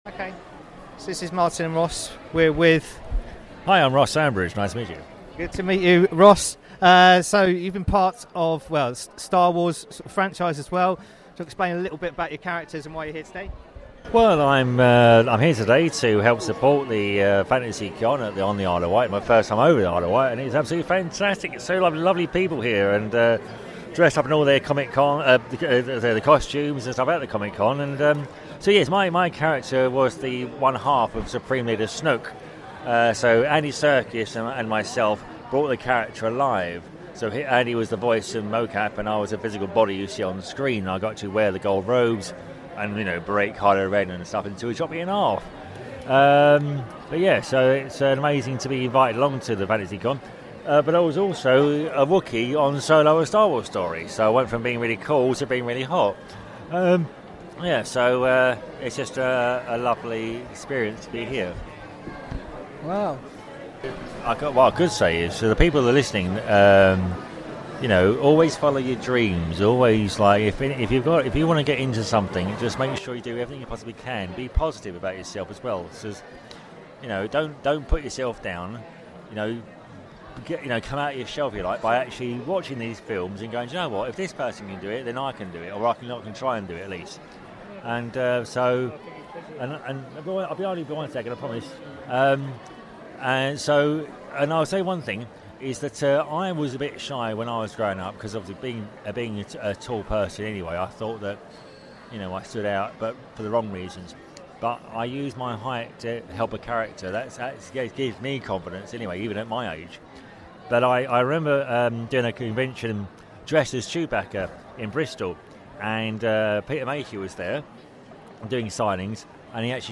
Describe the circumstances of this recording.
Interview with Star Wars at Fan Con